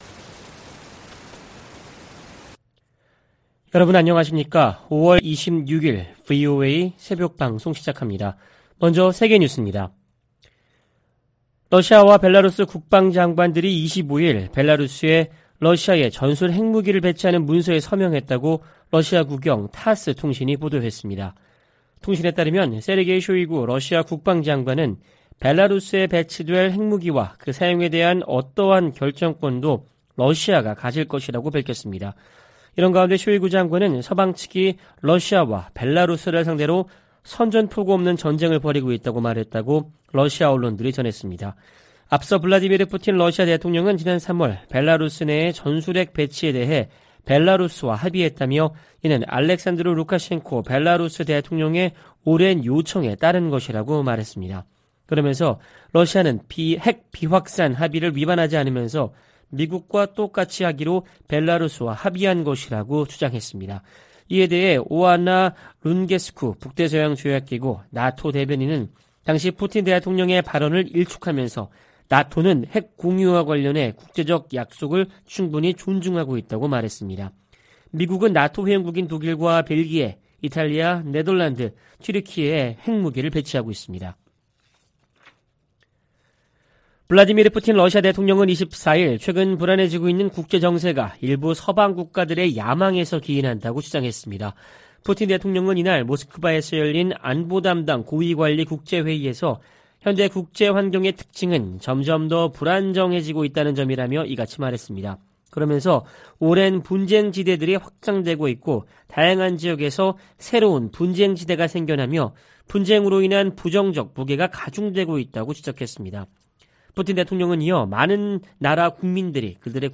VOA 한국어 '출발 뉴스 쇼', 2023년 5월 26일 방송입니다. 조 바이든 미국 대통령이 최근 미한일 정상회담에서 한일 정상의 관계 개선 노력을 높이 평가했다고 백악관 고위 관리가 밝혔습니다. 미군과 한국군이 25일부터 한반도 휴전선 인근 지역에서 역대 최대 규모 화력격멸훈련에 돌입했습니다. 북한 등 적국들이 미국 첨단 기술 획득을 시도하고 있다고 미 국무부 차관보가 밝혔습니다.